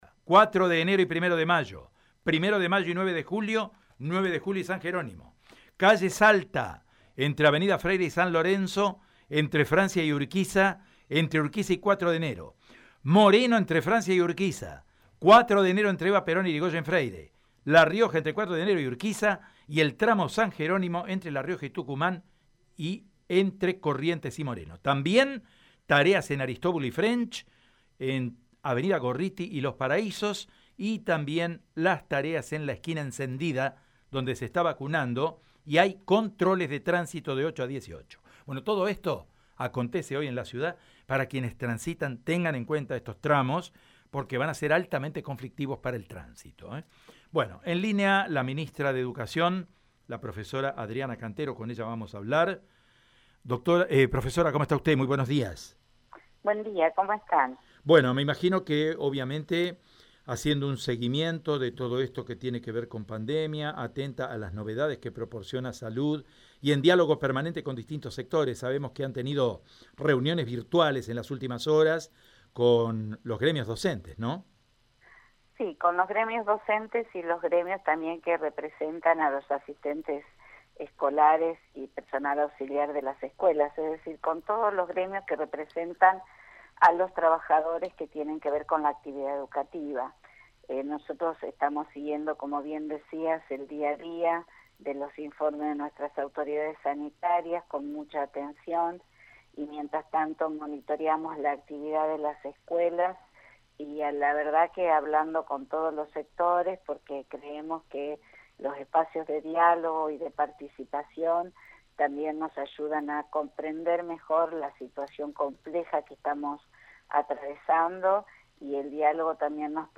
Así lo indicó la ministra de Educación de la provincia, Adriana Cantero.